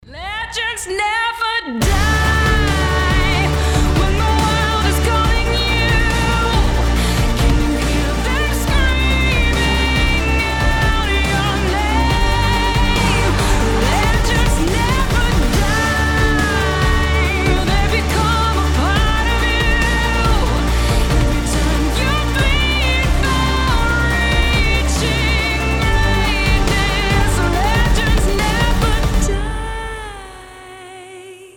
Cover
сильный голос